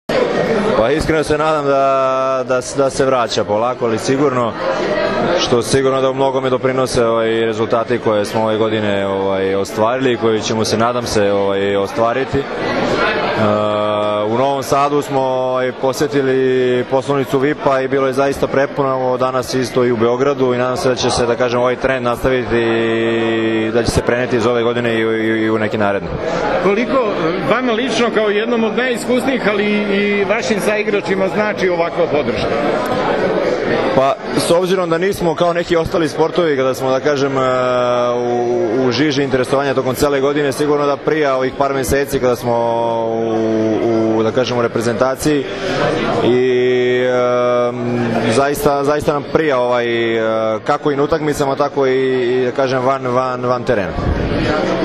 IZJAVA DRAGANA STANKOVIĆA